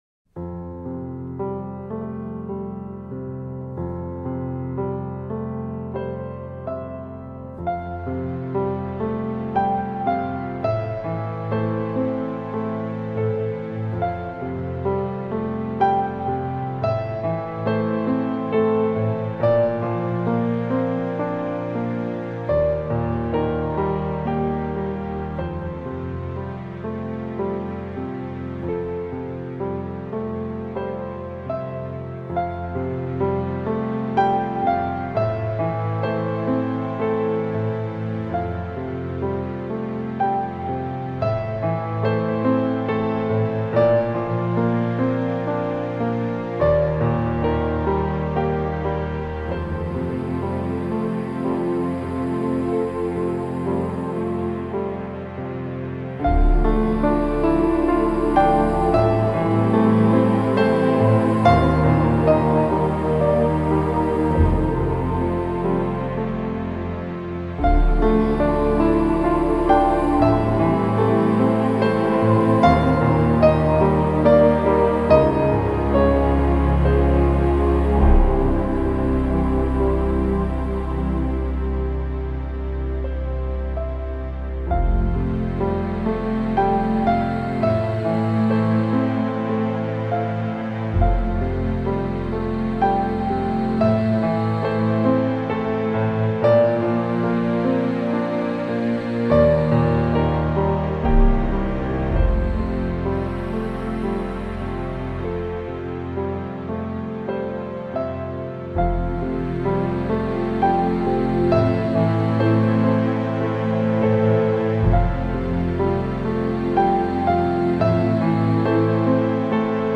It still calms me down.